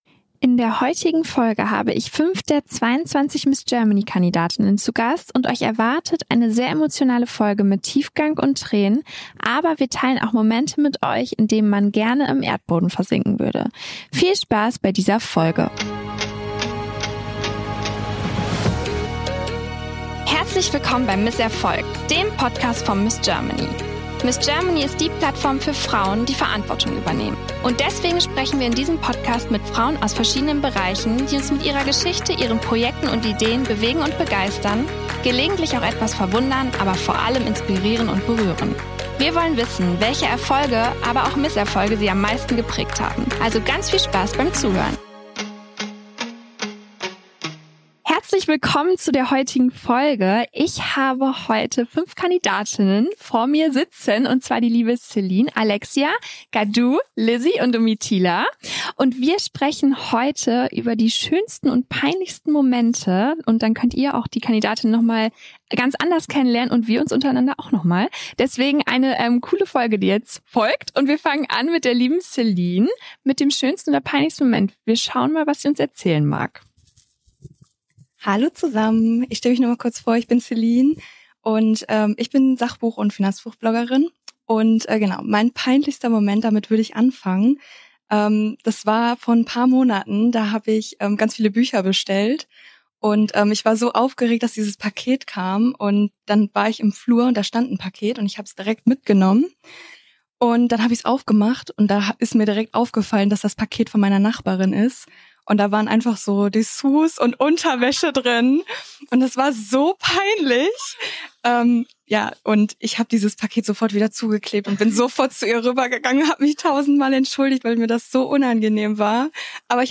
Die Folge ist nicht nur besonders, weil fünf unserer Kandidatinnen dabei sind, sondern sie ist auch so besonders, da wir uns gerade im Personality Camp im Europa-Park in Rust befinden.